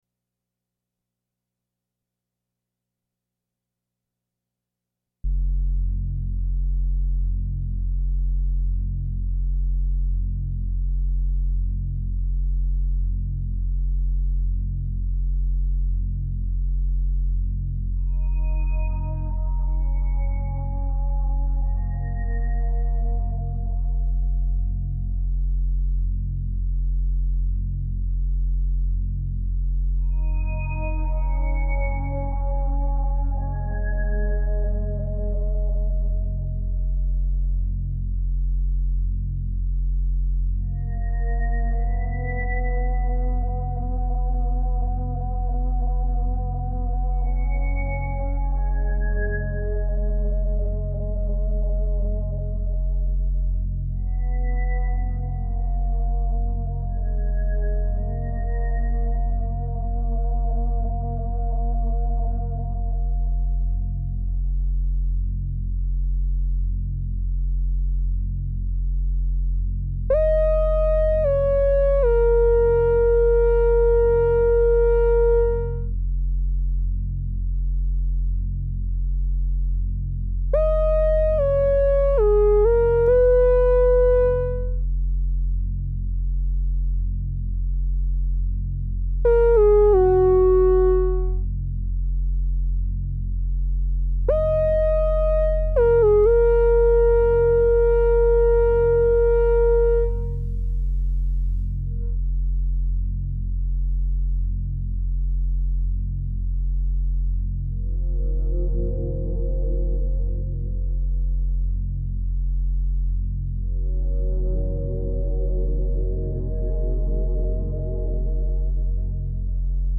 These were improvisations all recorded in late 2005.